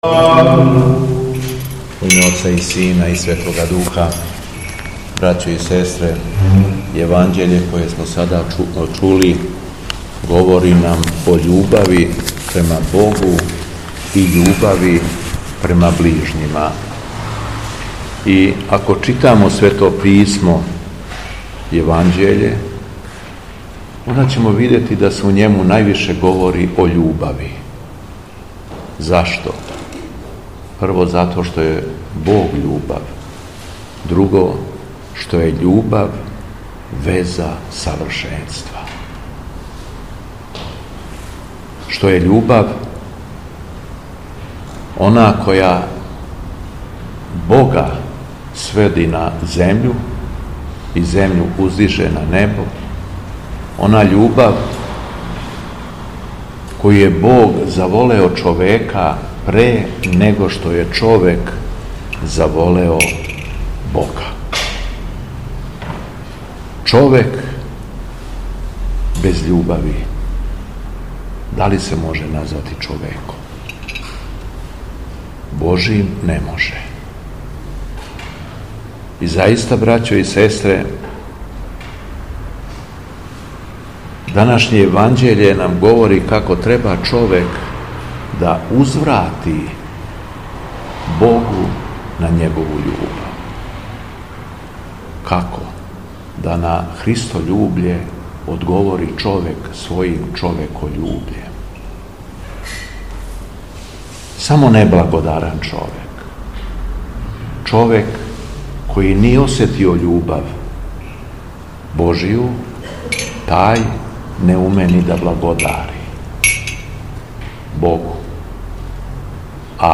СВЕТА АРХИЈЕРЕЈСКА ЛИТУРГИЈА У ХРАМУ СВЕТОГ ДИМИТРИЈА У СУШИЦИ - Епархија Шумадијска
Беседа Његовог Високопреосвештенства Митрополита шумадијског г. Јована
После прочитаног Јеванђеља Високопреосвећени владика се обратио верном народу беседом рекавши између осталог: